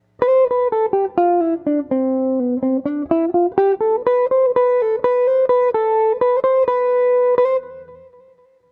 Escala diatônica maior
Escala Diatônica Maior – produz som mais aberto, expansivo, alegre, extrovertido.